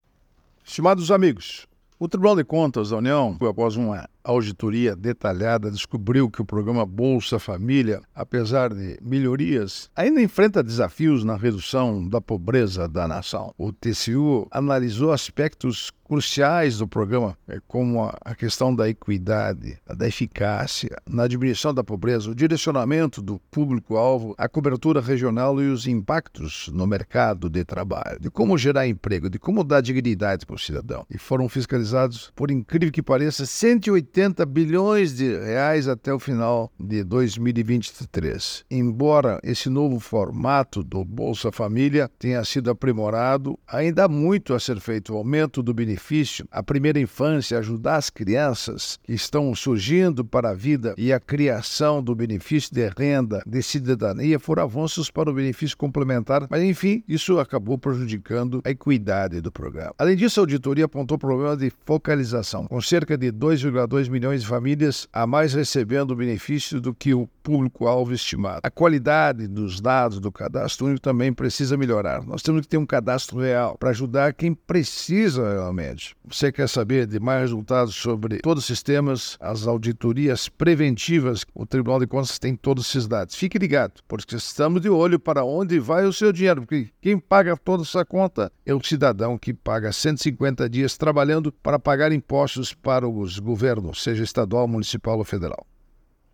É o assunto do comentário desta segunda–feira (16/09/24) do ministro Augusto Nardes (TCU), especialmente para OgazeteitO.